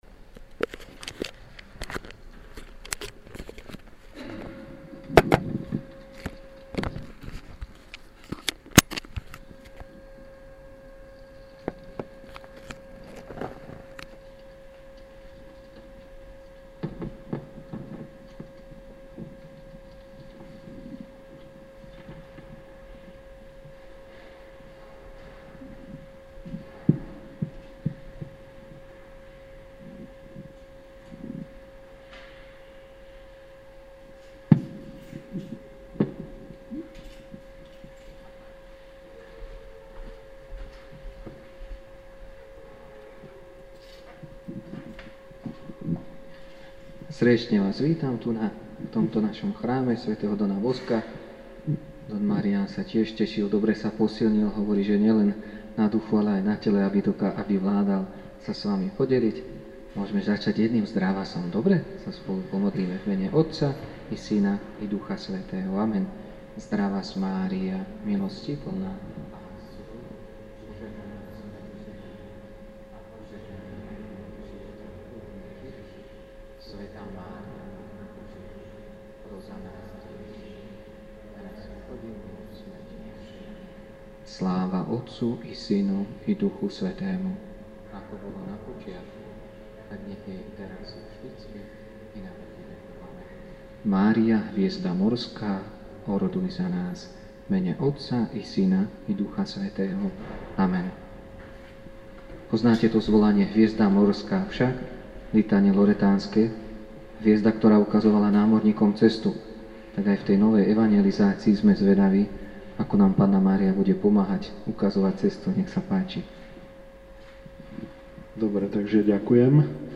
Keďže viaceré prednášky z pôstnej duchovnej obnovy mnohých povzbudili a oslovili, chceme možnosť vypočuť si ich dať širšej verejnosti.
Nedela prednaska.mp3